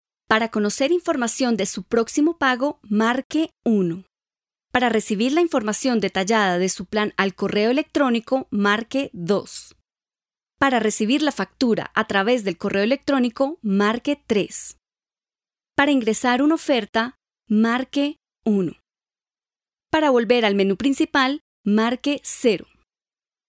kastilisch
Sprechprobe: Sonstiges (Muttersprache):